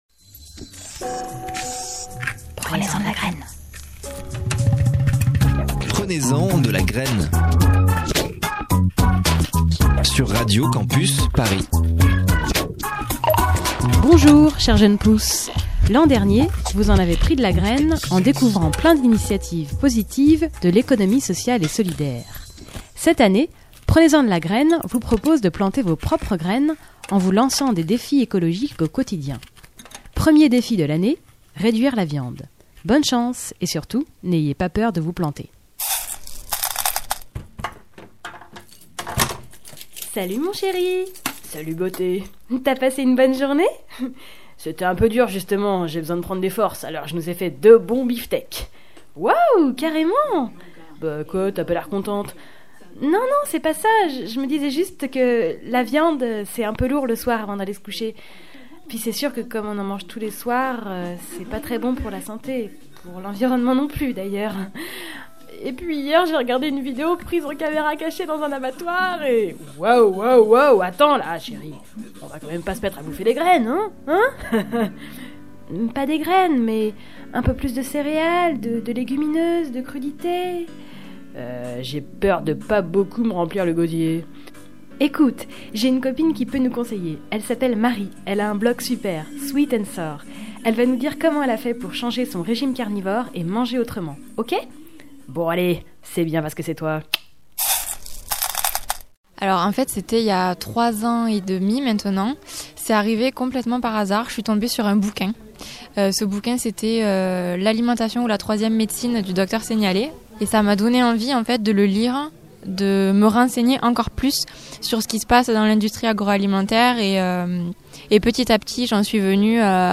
Nous essaierons de vous donner les plus simples, dans une émission légère et décalée...